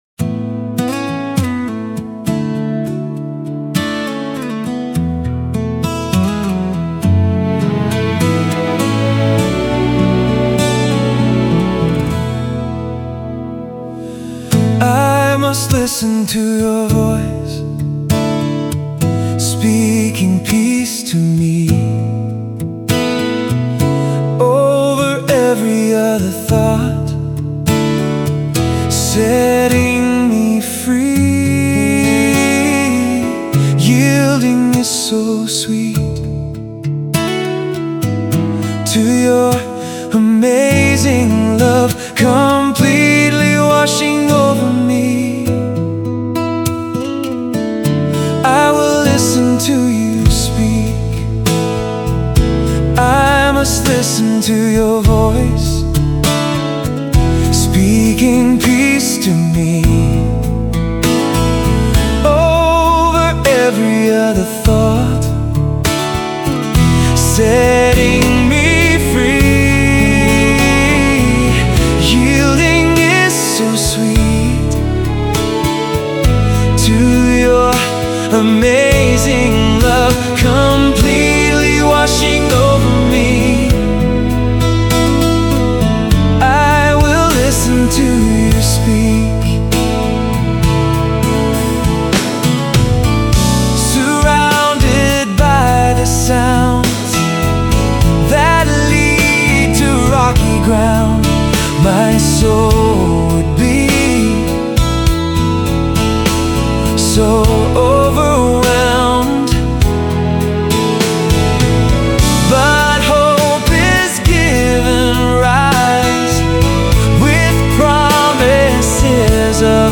Song – I Will Listen